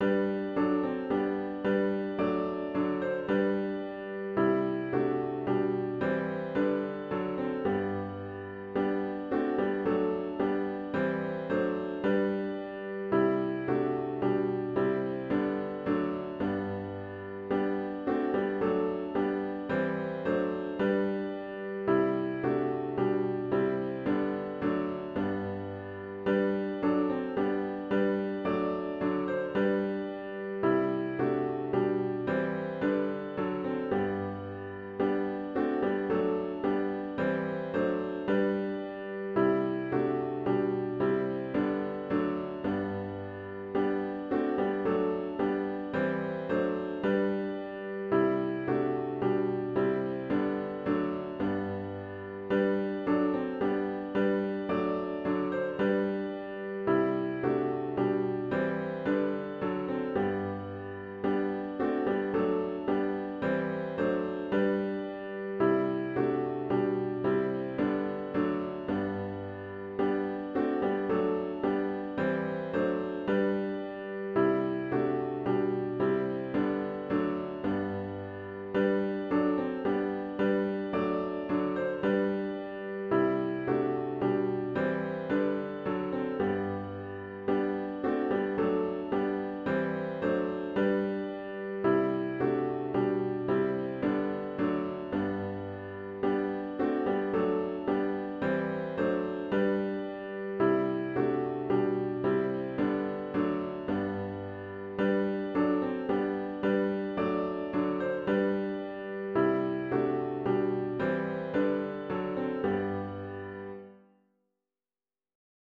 OPENING HYMN “For the Beauty of the Earth” GtG 14 (Verses 1, 2, 4, & 5)